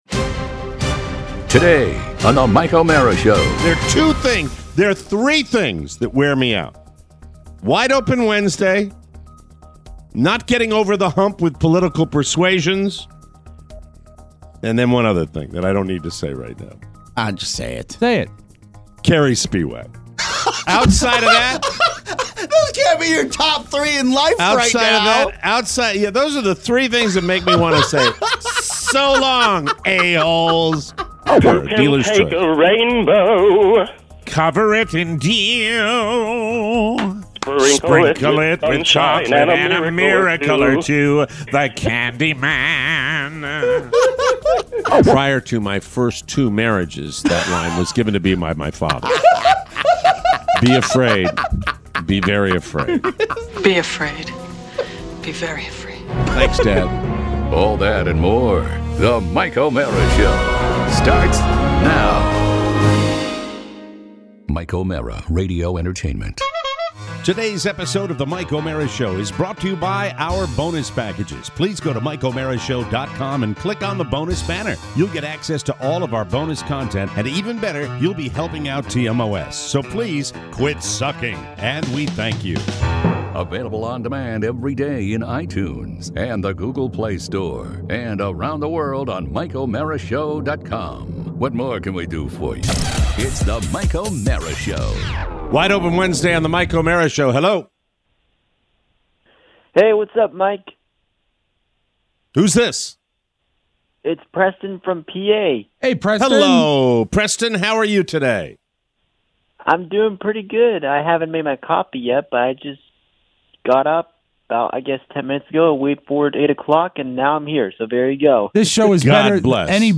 We take all the calls you got on Wide Open Wednesday!